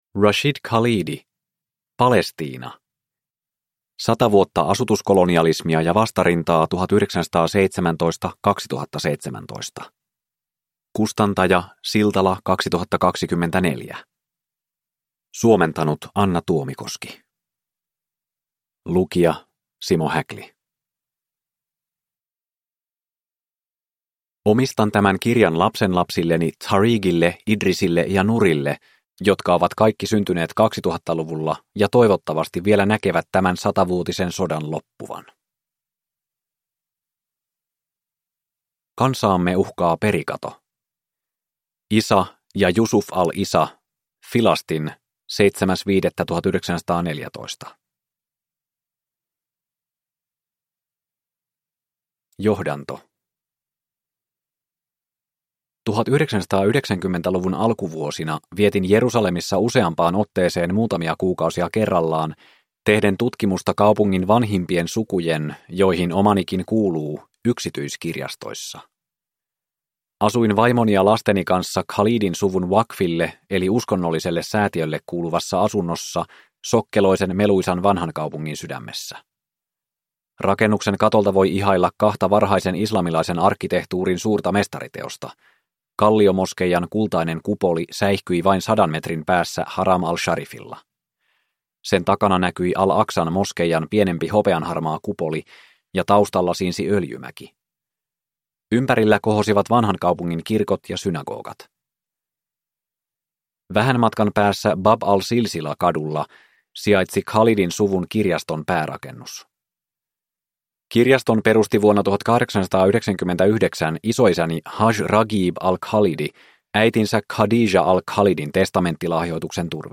Palestiina (ljudbok) av Rashid Khalidi